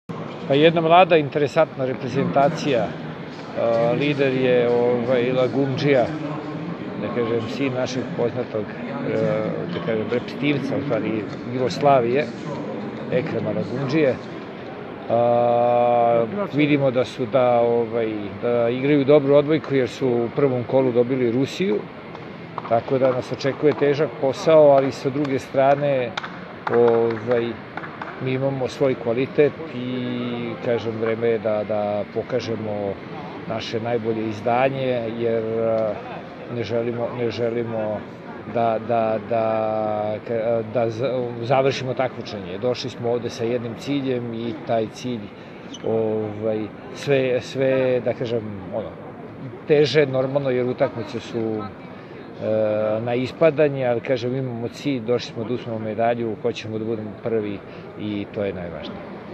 Izjava Slobodana Kovača